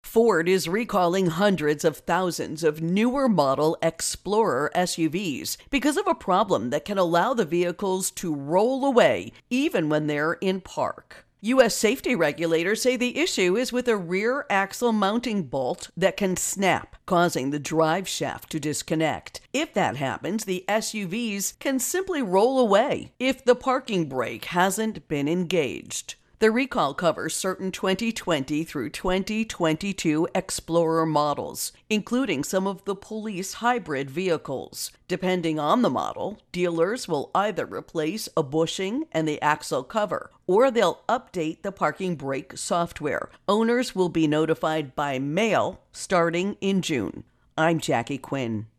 Ford Recall Intro and Voicer